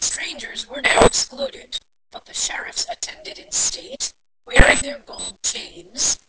Also the audio output quality is pretty poor